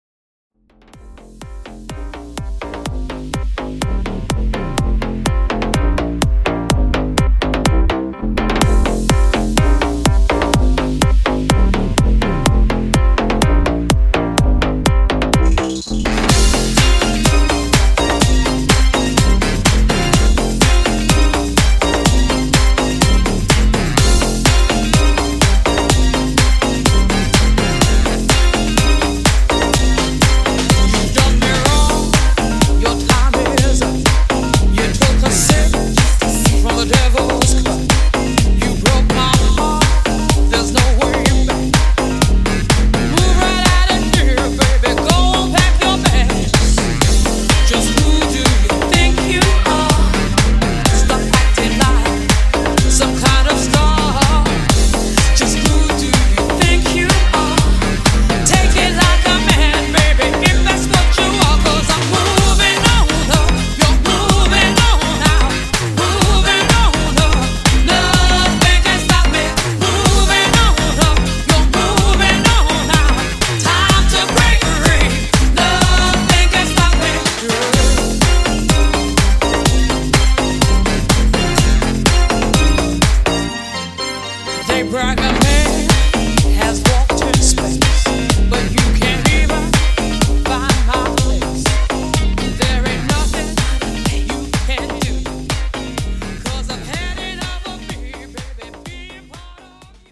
Bass House)Date Added